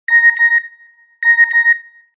Короткие рингтоны
Рингтоны на смс и уведомления
Electronic , Звонкие